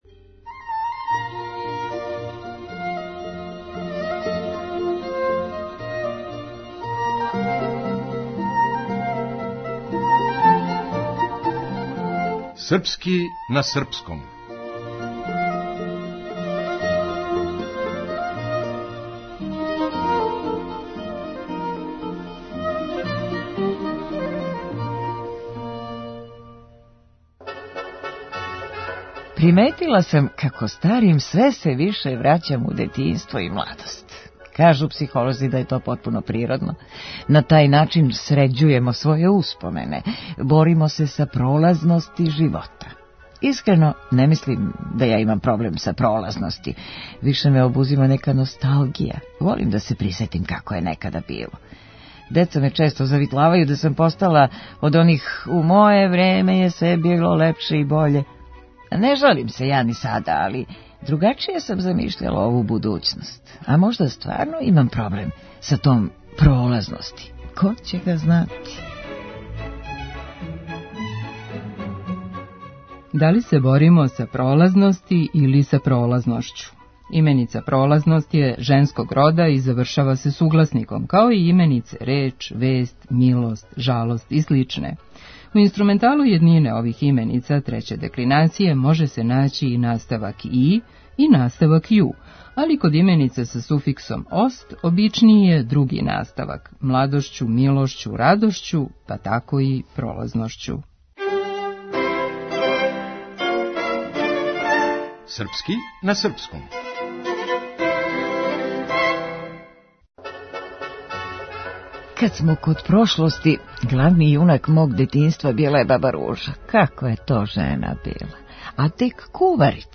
Глумица